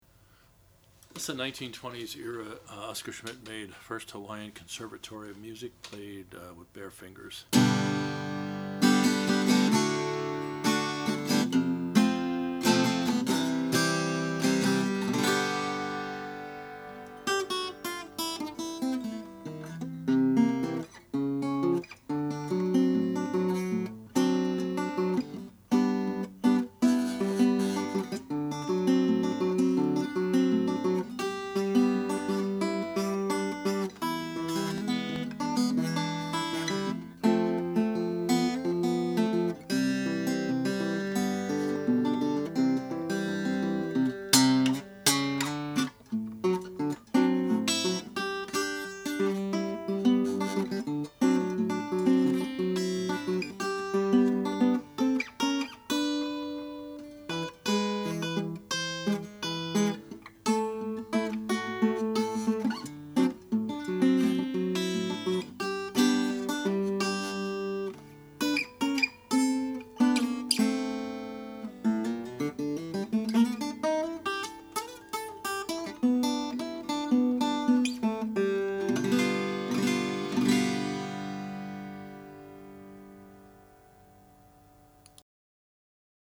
This is a great playing and looking example of a popular blues guitar. The boxy, woody ladder-braced tone can take you right back to the age of 78 rpm records!